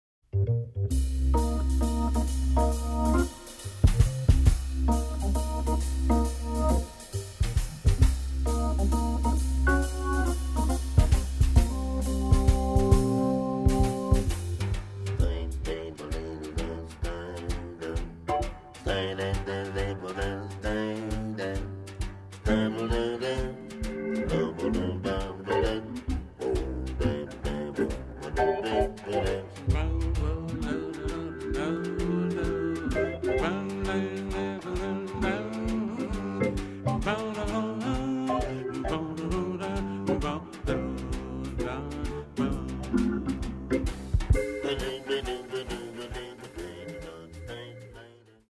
Acoustic Bass and Vocal
Acoustic Piano and Hammond Organ
Drums
Witty jive.